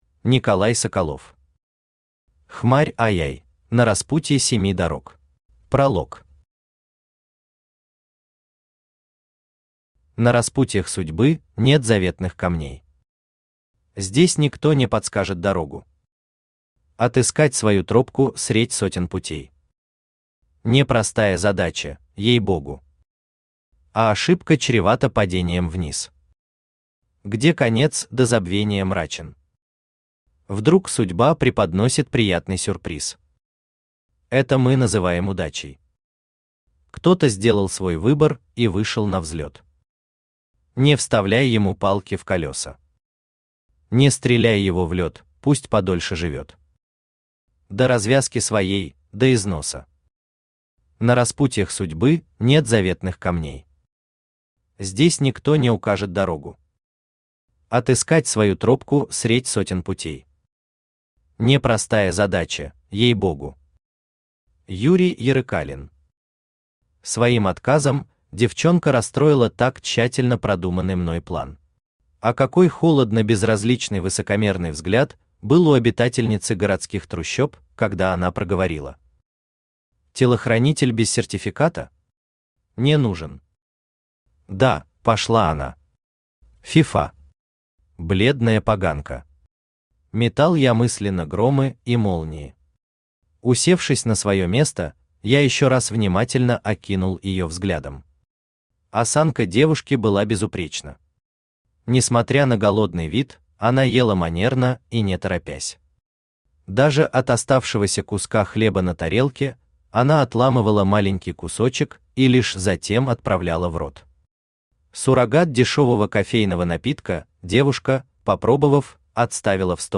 Аудиокнига Хмарь II. На распутье семи дорог | Библиотека аудиокниг
Aудиокнига Хмарь II. На распутье семи дорог Автор Николай Александрович Соколов Читает аудиокнигу Авточтец ЛитРес.